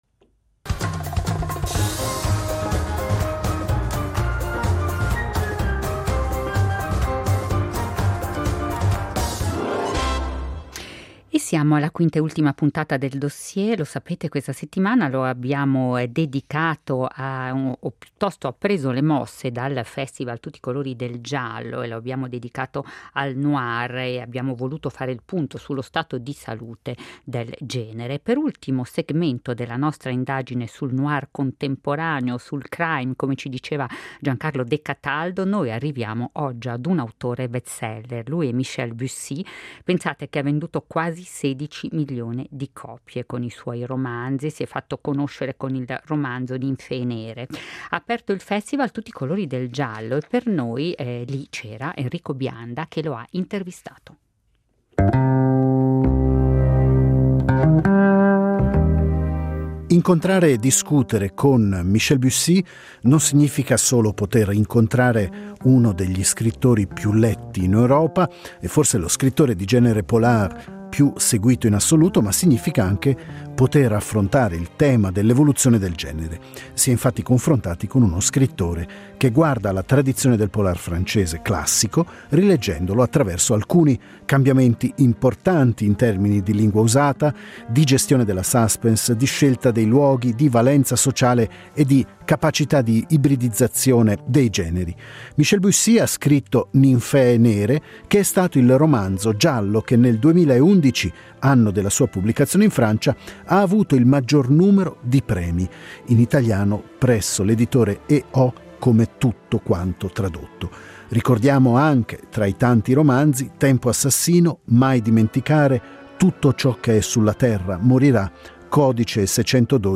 Bussi ha aperto il festival Tutti i colori del giallo, per noi l’occasione di incontrarlo per un’intervista